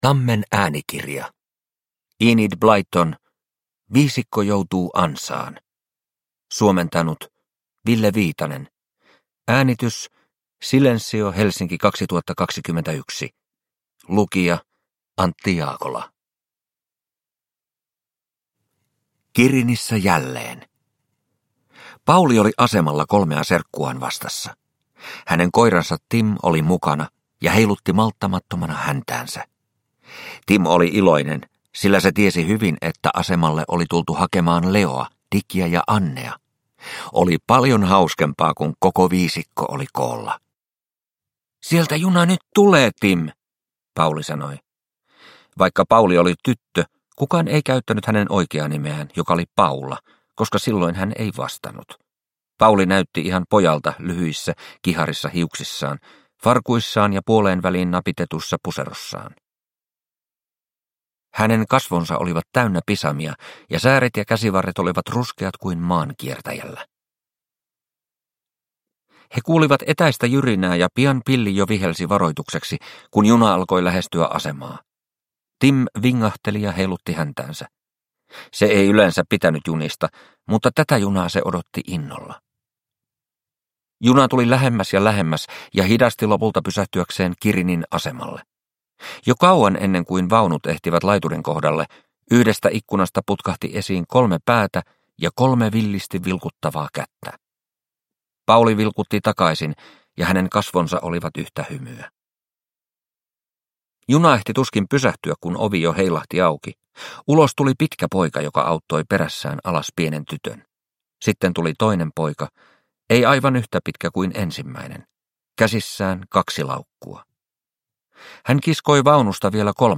Viisikko joutuu ansaan – Ljudbok – Laddas ner